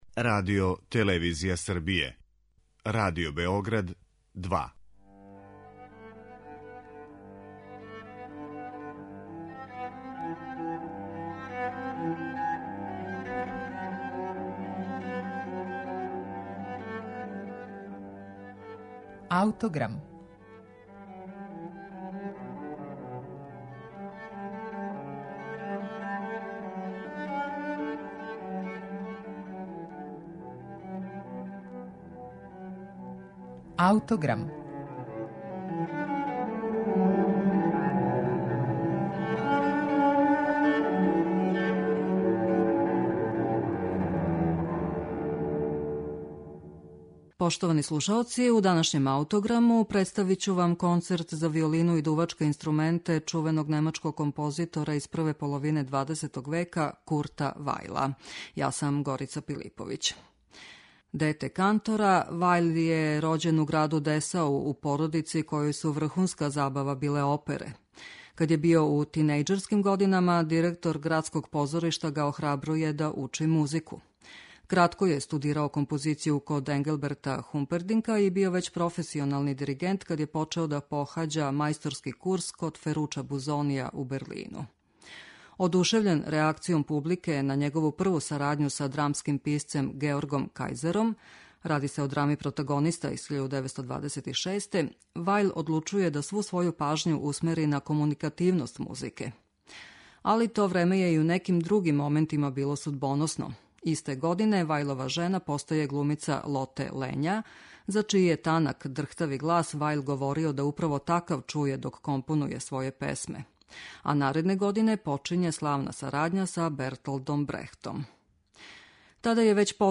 Слушаћете концерт за виолину и дувачке инструменте из опуса Курта Вајла, немачког композитора из прве половине XX века, познатог и по сарадњи са Бертолдом Брехтом.